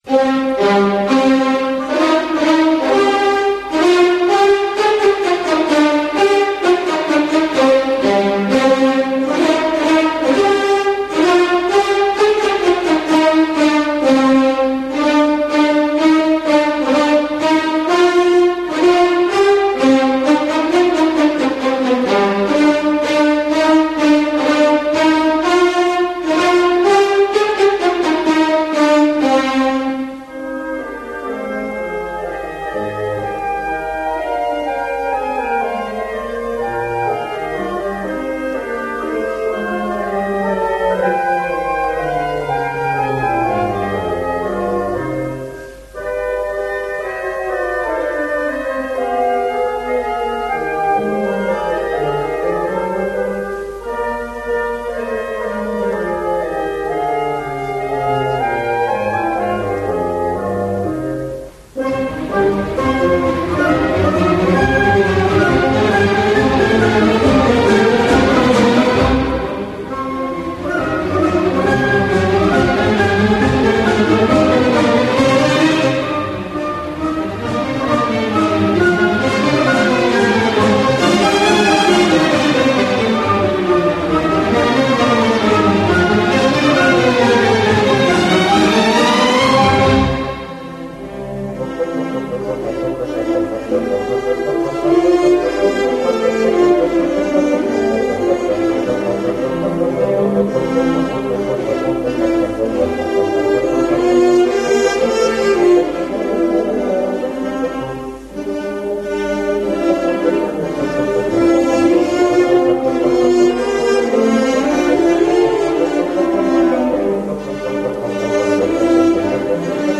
Aудиокнига Арлезианка